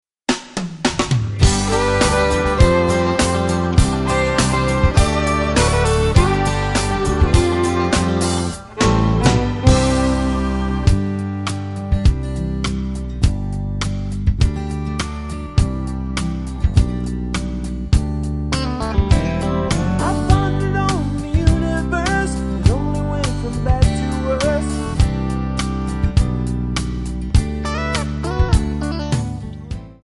C#
MPEG 1 Layer 3 (Stereo)
Backing track Karaoke
Country, 2000s